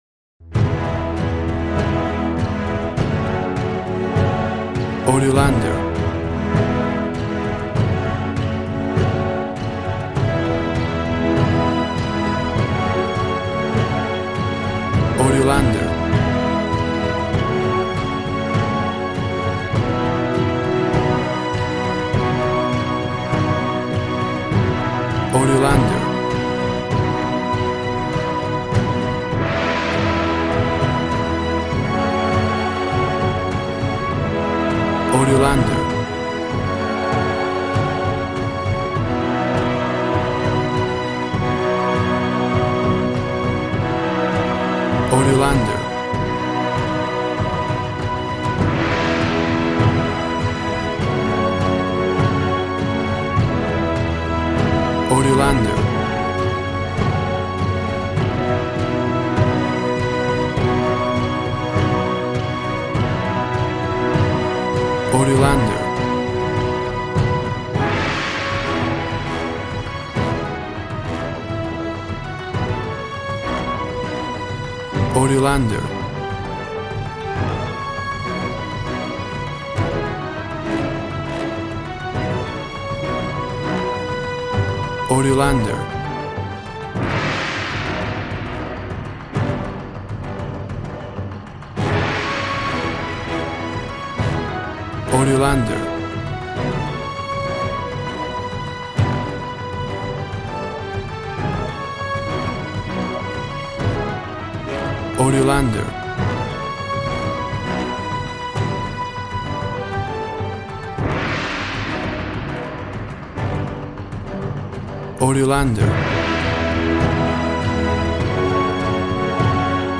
Action and Fantasy music for an epic dramatic world!
Tempo (BPM) 100